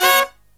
HIGH HIT01-L.wav